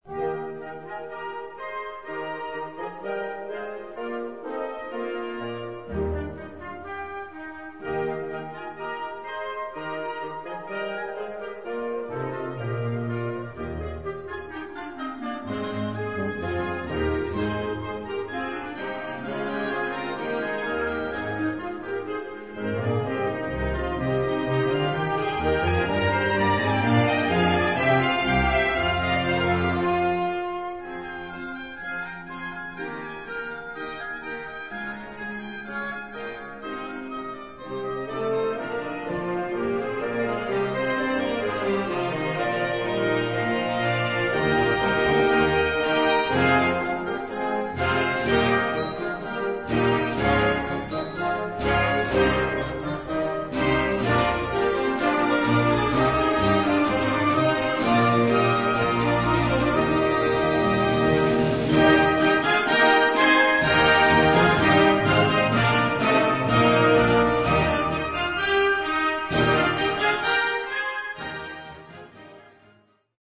Categorie Harmonie/Fanfare/Brass-orkest
Subcategorie Suite
Bezetting Ha (harmonieorkest)